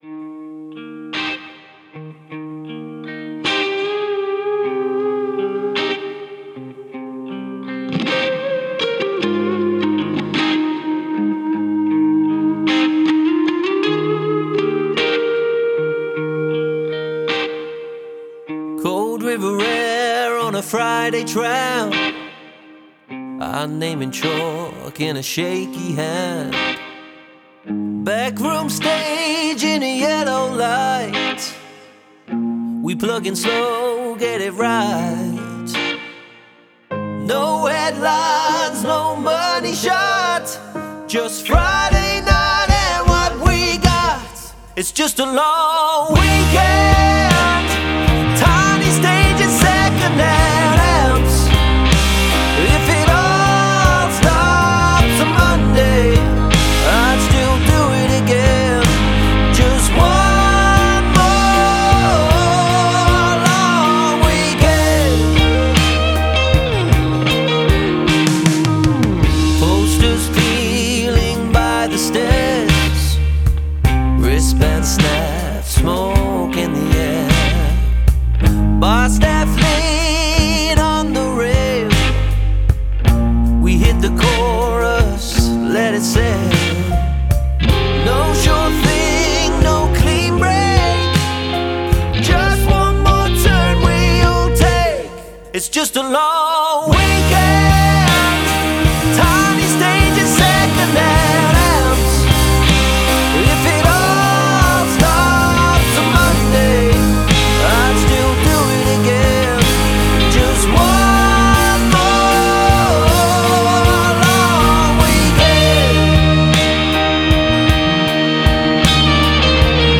indie atmosférico
uma sonoridade mais calorosa e atmosférica
Vocalist